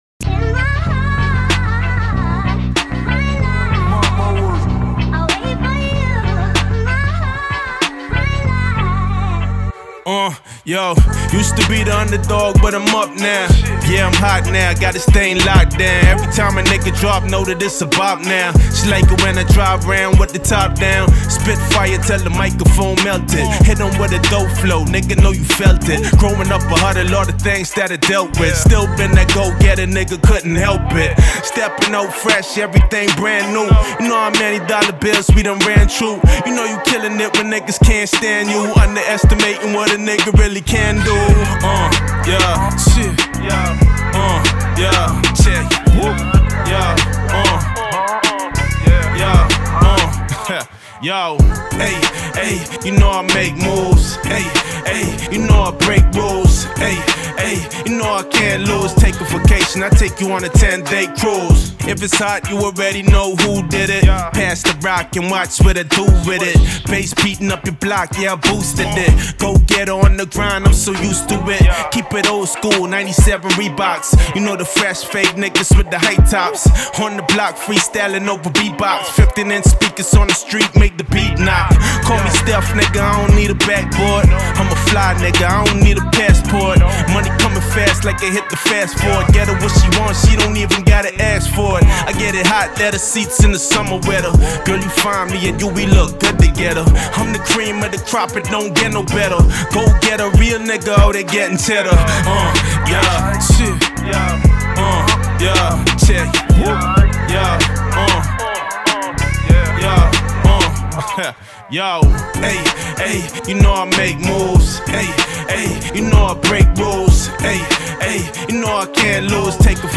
Fully mixed acapella